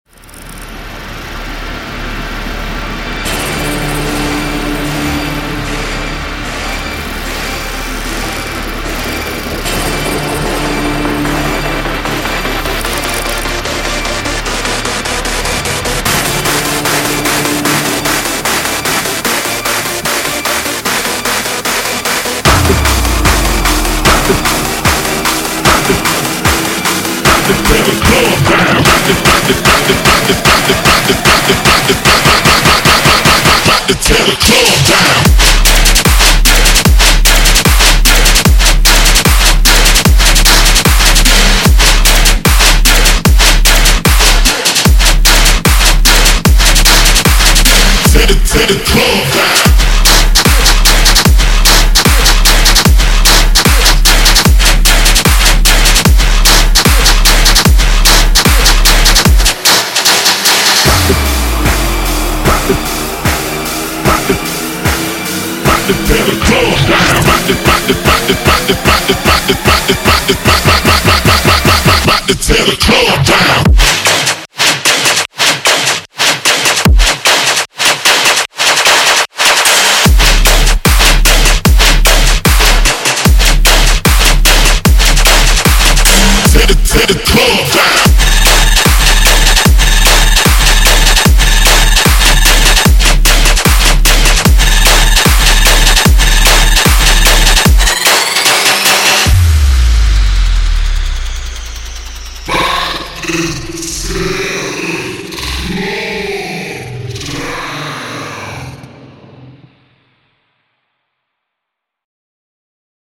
BPM150
Audio QualityPerfect (High Quality)
brought together by a love for dubstep and friendly tech.
hard-hitting, sky-cutting track